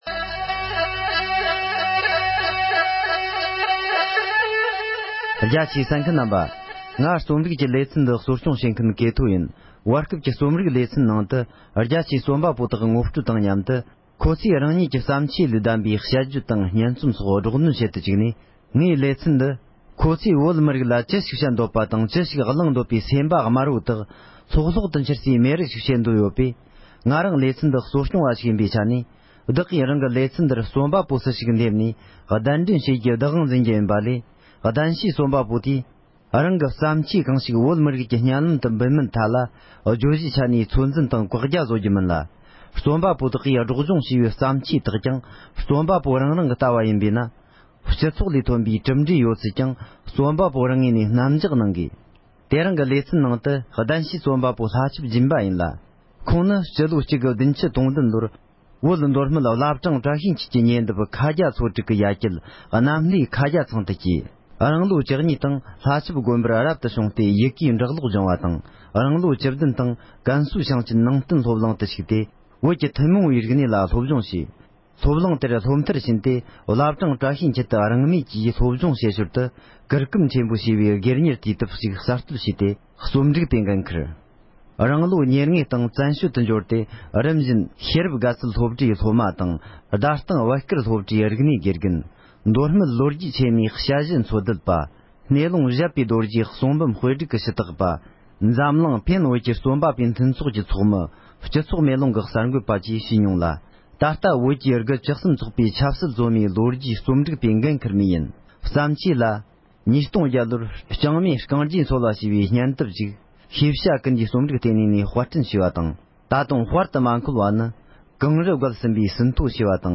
རྣམ་རྟོག་གིས་འཆི་བའི་ཚེ་སྲོག་ཅེས་པའི་སྙན་རྩོམ་དང་དེའི་ཕྲན་བཤད་སྒྲོག་འདོན་བྱས་པ་ཞིག་ལ་གསན་རོགས་གནོངས༎